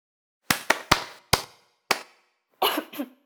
applause-d.wav